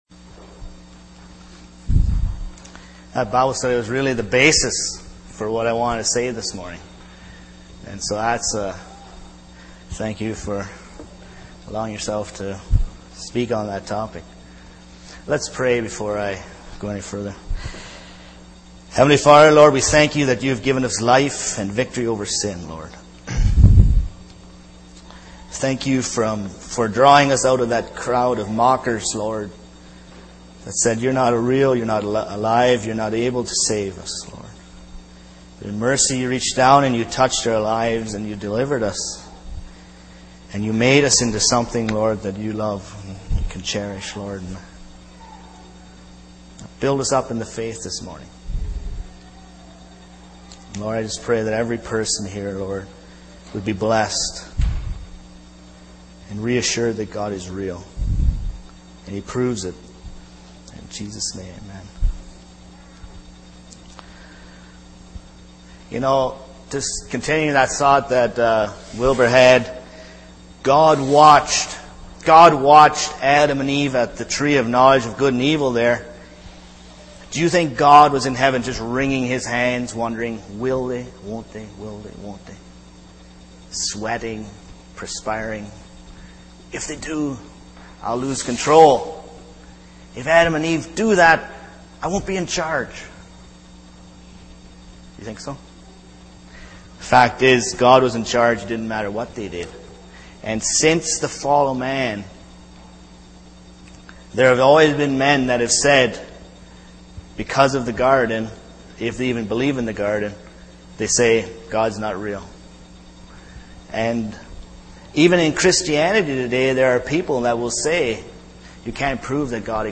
Sunday Morning Sermon Passage: Deuteronomy 18:15-22 Service Type: Sunday Morning